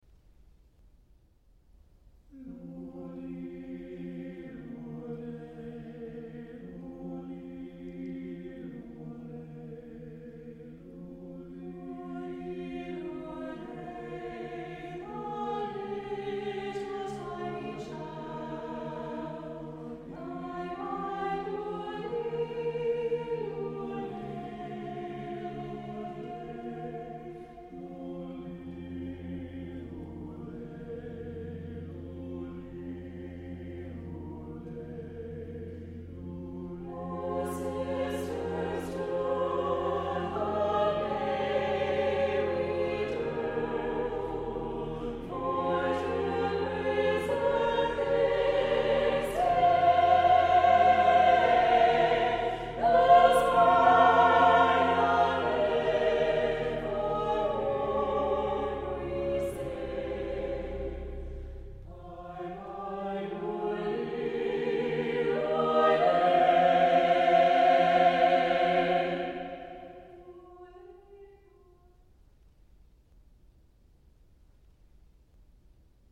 • Music Type: Choral
• Voicing: SATB
• Accompaniment: a cappella